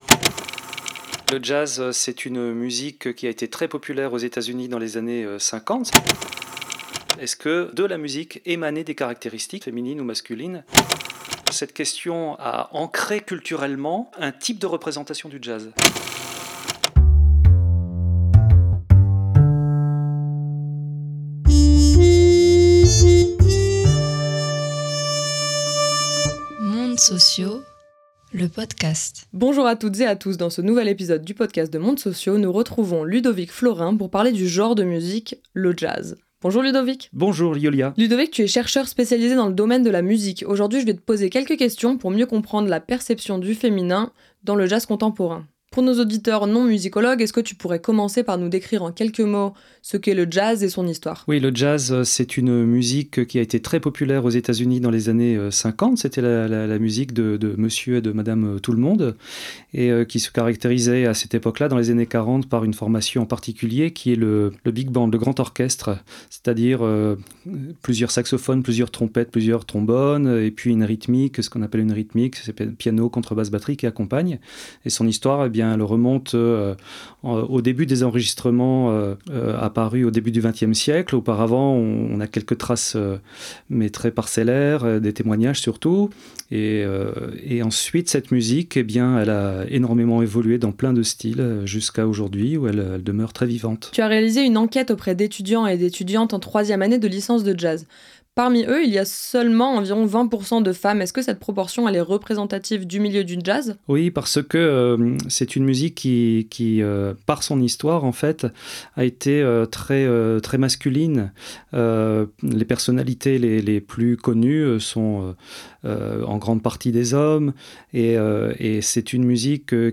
Sous forme de dialogue avec son auteur ou autrice, ces podcasts discutent d’un article scientifique publié récemment.